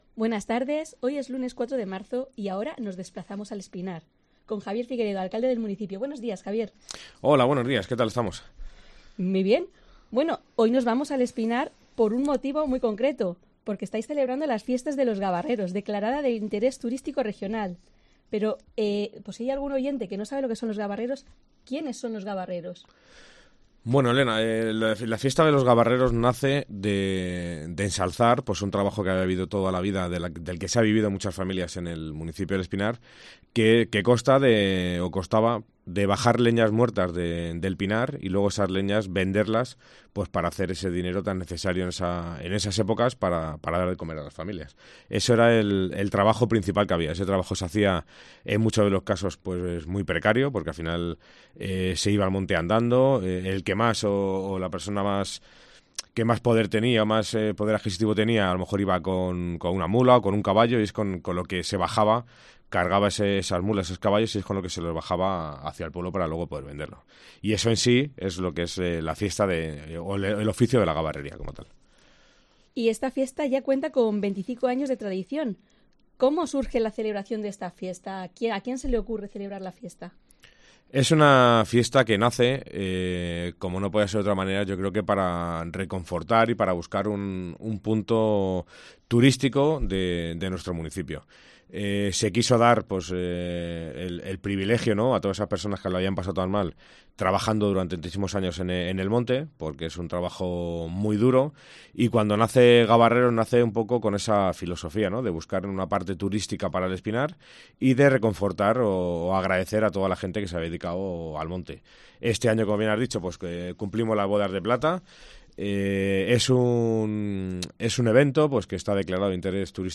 AUDIO: Es el alcalde del municipio segoviano de El Espinar